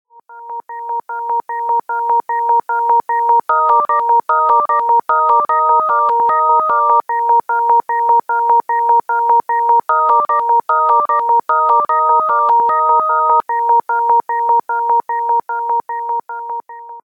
a PLD-based modular electronic organ
This is a design of an electronic organ making use of DDS-based sound generation with programmable logical devices (PLDs).
Next to the individual adjustment of the basic sound wave by distorting the R2R-ladder, a filter feedback is available for a more living sound.
12 channel sound generation